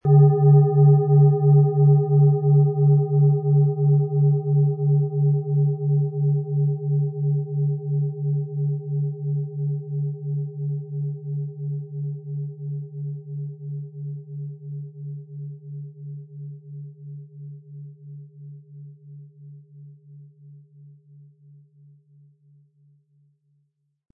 Planetenschale® Heiter Sein & Unabhängig werden mit Merkur & Uranus, Ø 19,2 cm inkl. Klöppel
• Mittlerer Ton: Uranus
PlanetentöneMerkur & Uranus
HerstellungIn Handarbeit getrieben
MaterialBronze